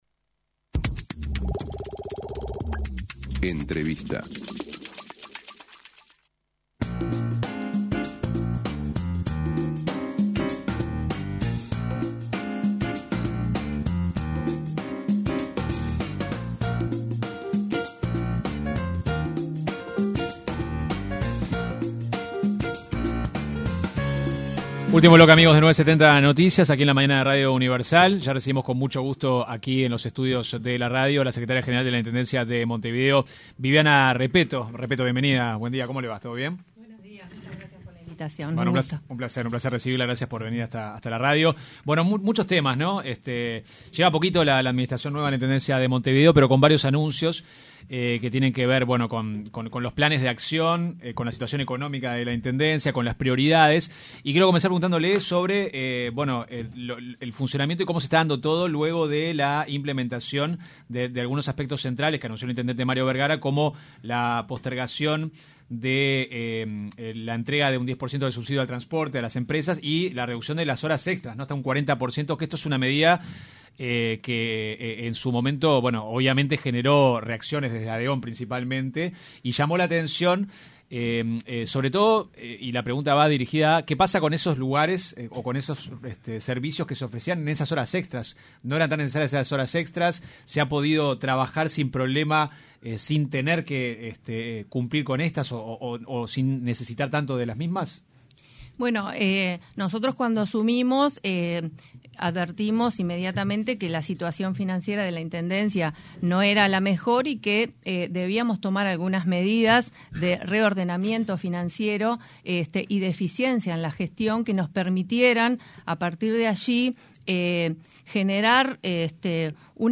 AUDIO La secretaria general de la Intendencia de Montevideo, Viviana Repetto, detalló en diálogo con 970 Noticias, las medidas tomadas por la comuna con el objetivo de disminuir los gastos.